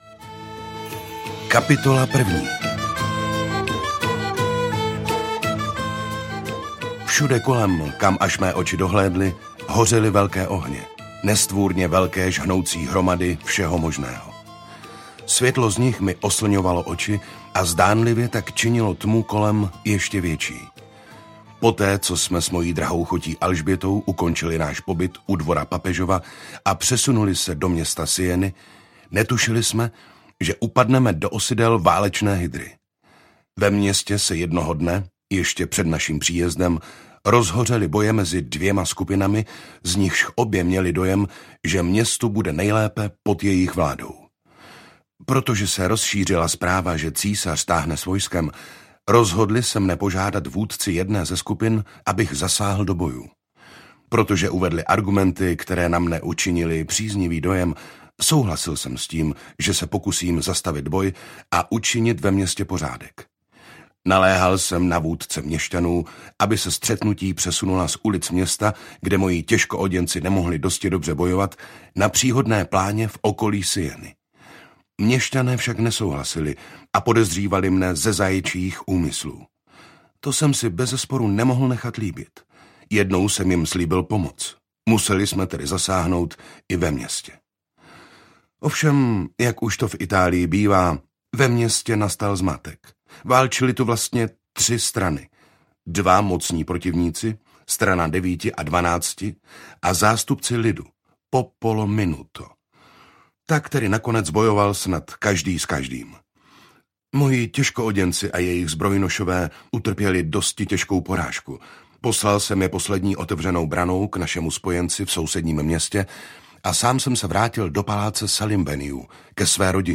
Ukázka z knihy
• InterpretJiří Dvořák